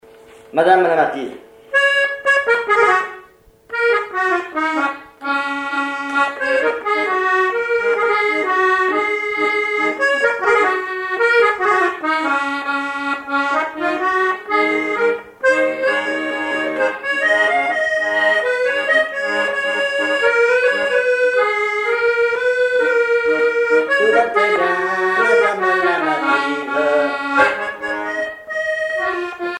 accordéon(s), accordéoniste
danse : valse musette ; danse : marche
Pièce musicale inédite